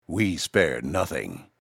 He sounds really sinister and I fully expect him to have a large scar down the front of his face.
This guy sounds like he's going to hide in my tiny little cave of an office and kill me when I least expect it.